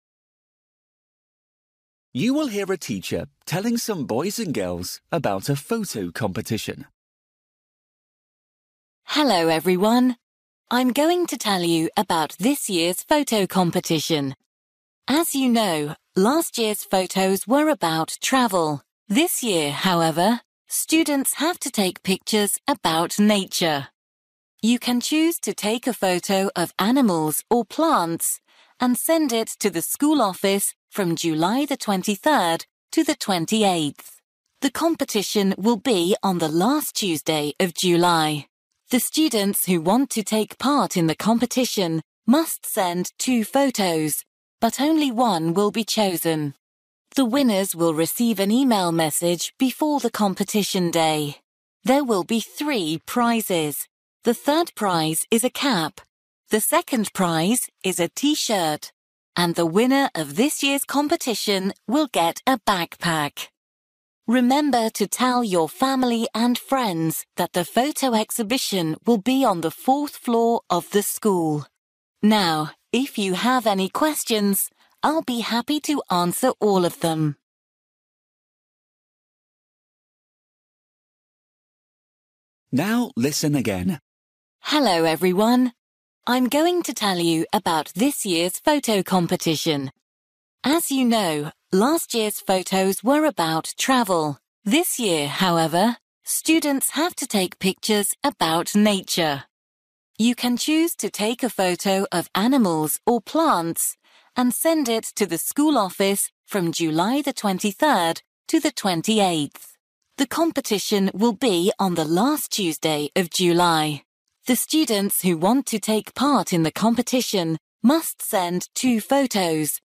You will hear a teacher telling some boys and girls about a photo competition.